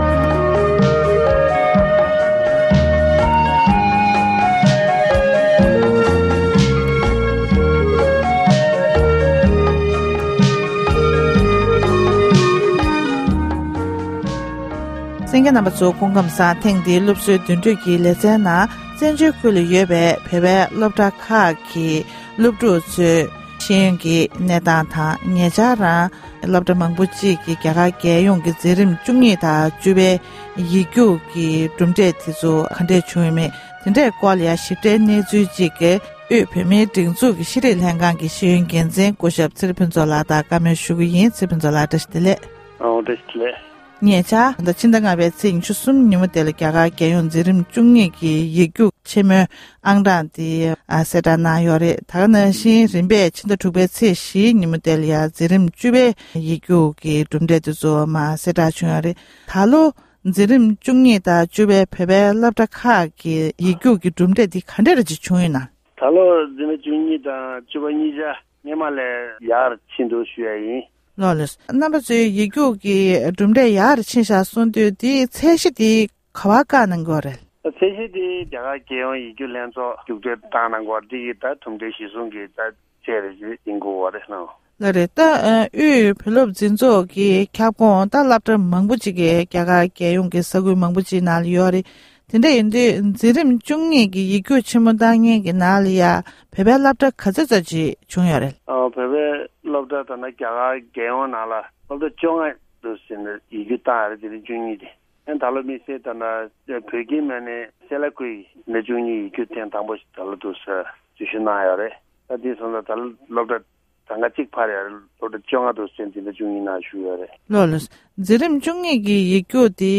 གནས་འདྲི་ཞུས་ཡོད༎